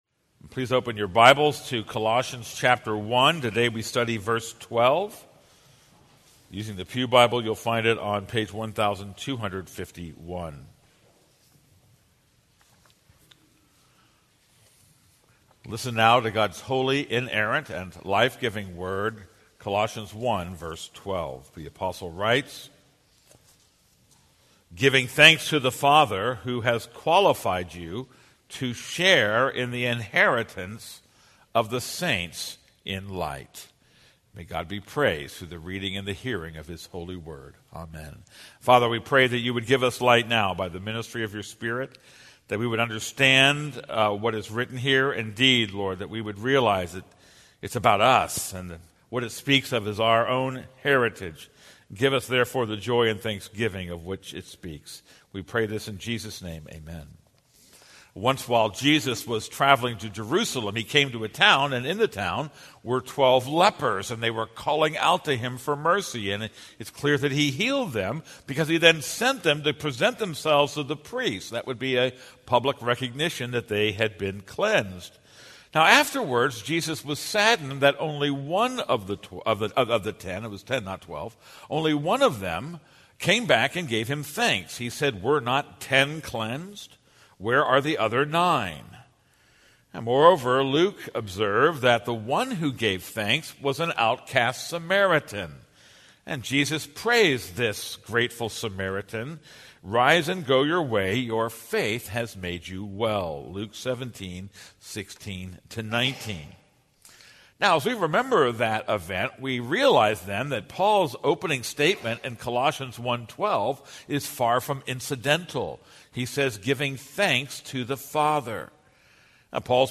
This is a sermon on Colossians 1:12.